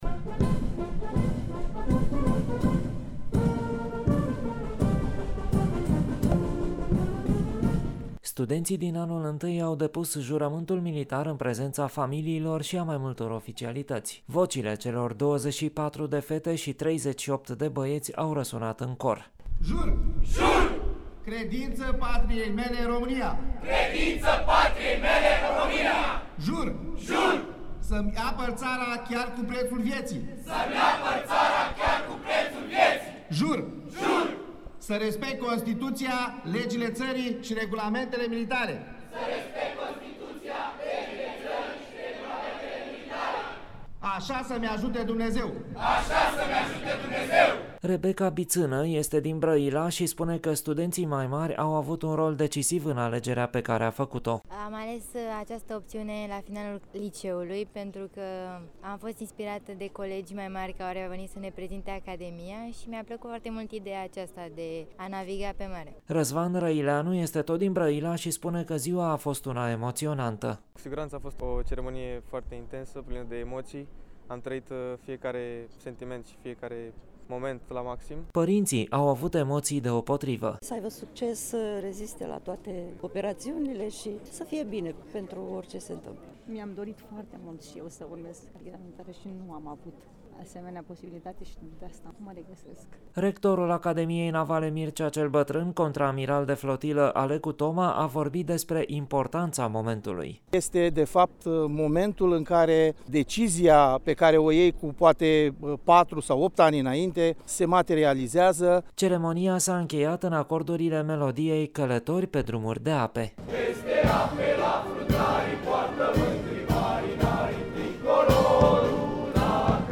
La ceremonie, au participat alături de studenţi, membri ai familiilor acestora, personalul didactic, cadre militare în activitate şi în rezervă.